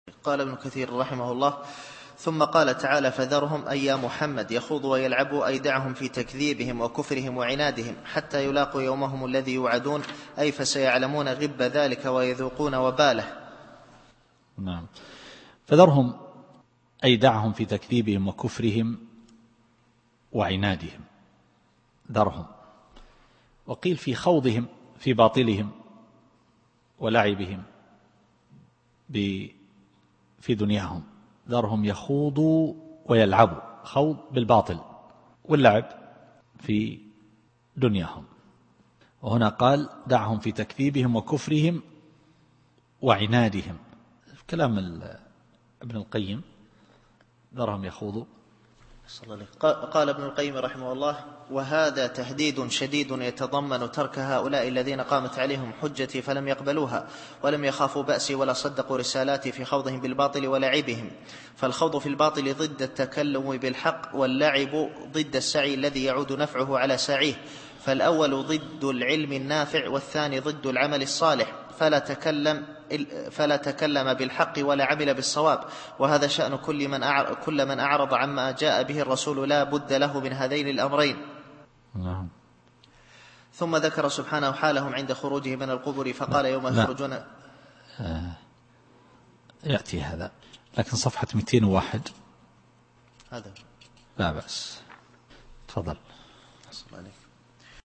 التفسير الصوتي [المعارج / 42]